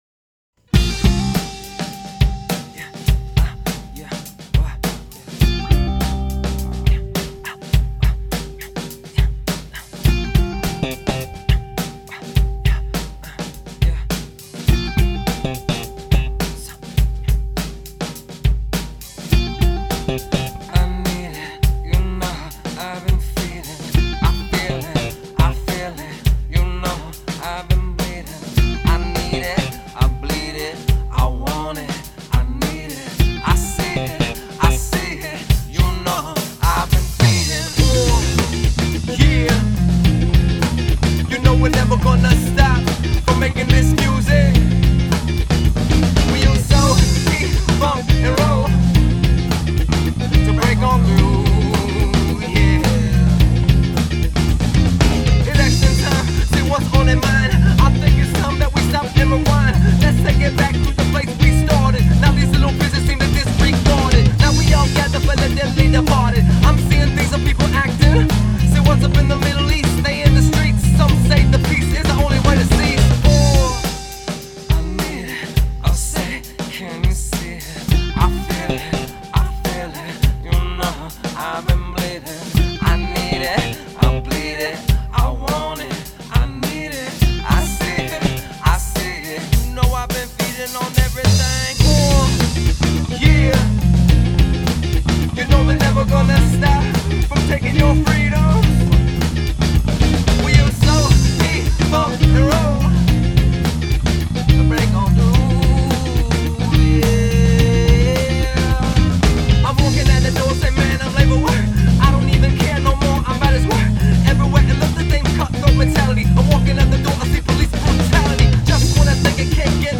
Catch A Felony - Alt Rock / Hip Hop, looking for EQ advice
We've gone through a few rounds of mixing but still can't quite get the track to sound the way I want. Right now it sounds very "muffled" to me, especially on speakers that have decent bass.